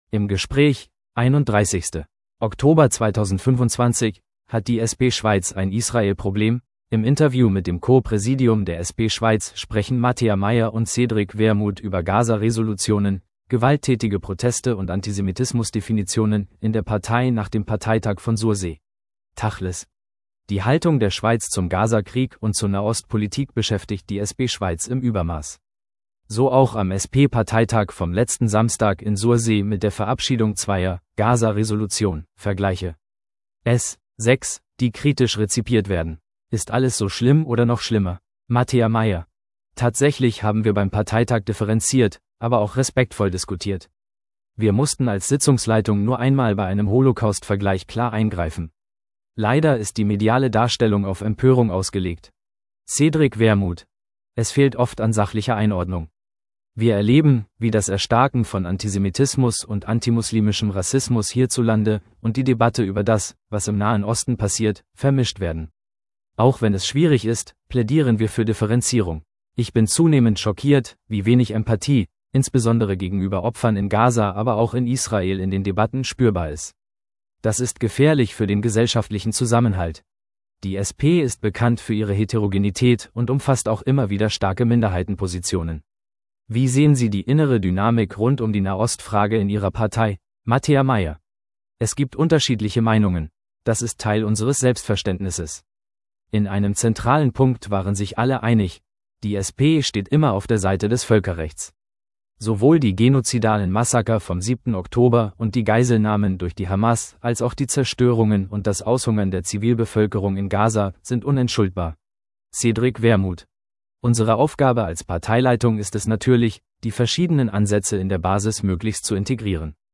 Im Interview mit dem Co-Präsidium der SP Schweiz sprechen Mattea Meyer und Cédric Wermuth über Gaza-Resolutionen, gewalttätige Proteste und Antisemitismusdefinitionen in der Partei nach dem Parteitag von Sursee.